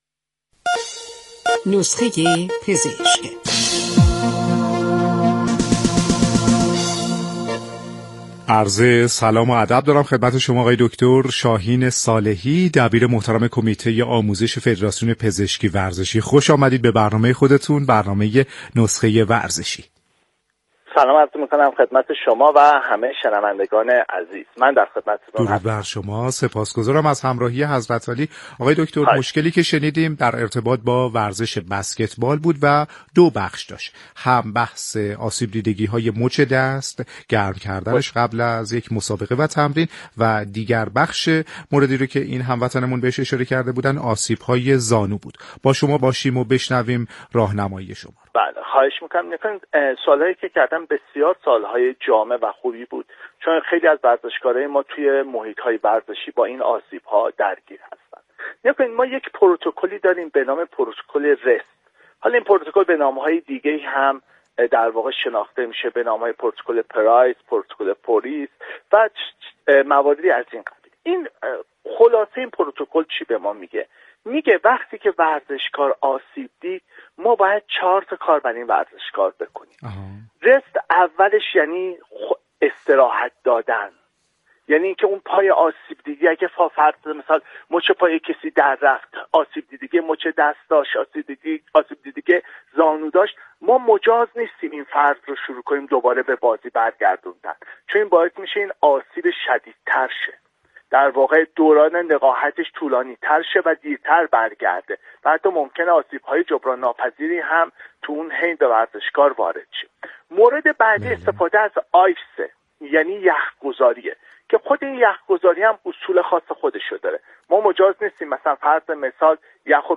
در گفت وگو با برنامه نسخه ورزشی رادیو ورزش.